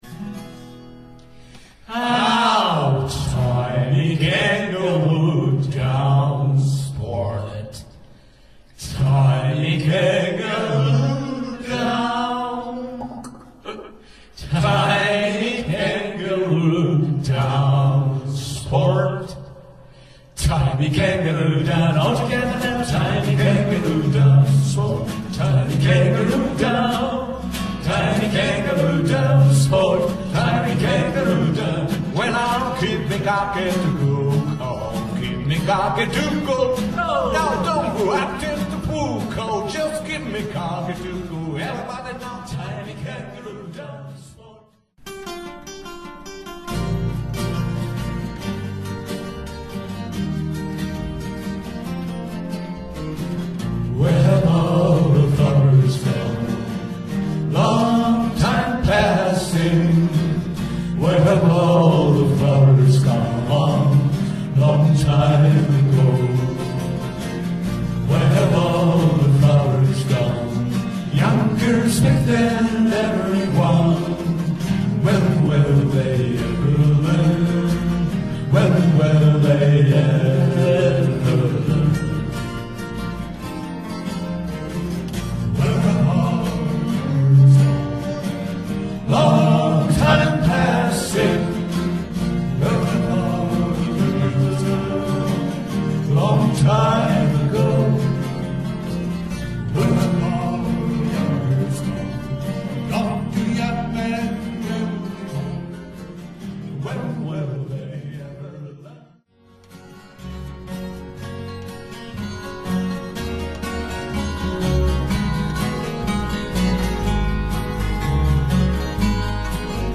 台風接近の３０日（日）午前、「嵐の前の静けさ」の中、某スタジオで数時間、久しぶりの練習を敢行しました。
今回はキングストンの曲でも４人で歌えるように、ソロの取り方などをオリジナルから変更して演奏するなど、工夫も。 練習の様子一部はこちらから聴くことができます。